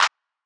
Metro Clap.wav